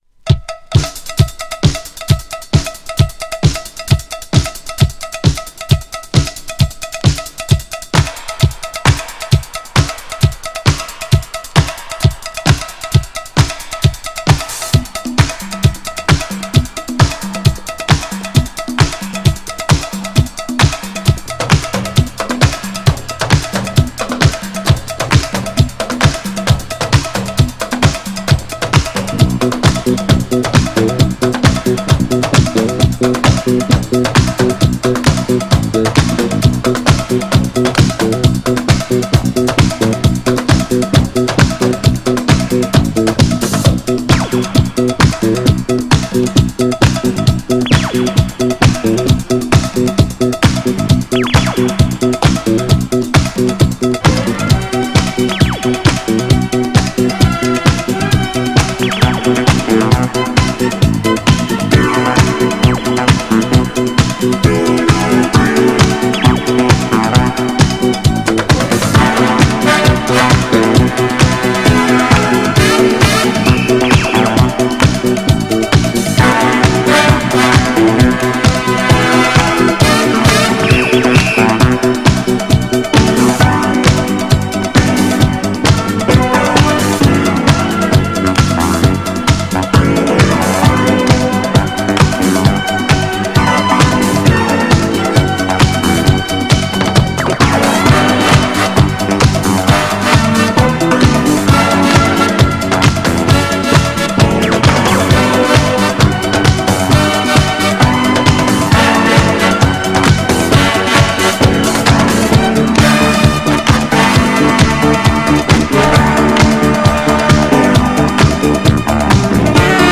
GENRE Dance Classic
BPM 111〜115BPM
INSTRUMENTAL # ITALO_DISCO
ドラムブレイク
パーカッシブ